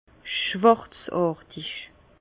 Bas Rhin schwarzàrtig
Ville Prononciation 67 Herrlisheim